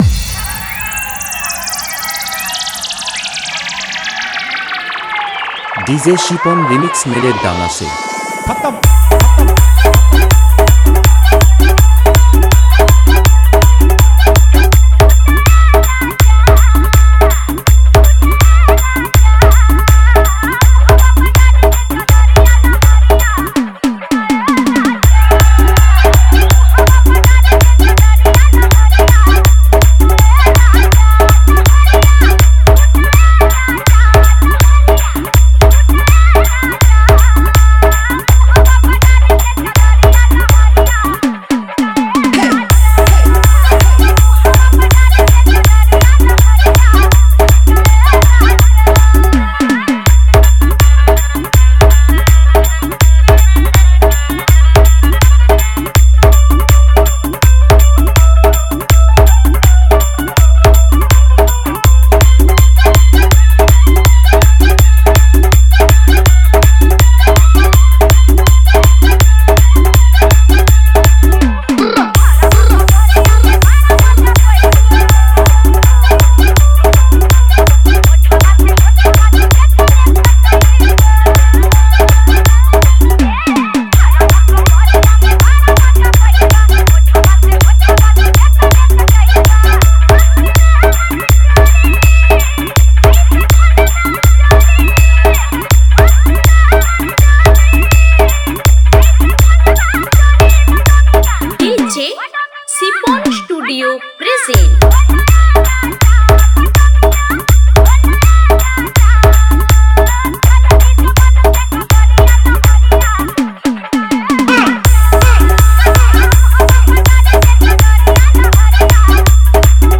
Bhojpuri Dance Humming Bass Mix song new 2025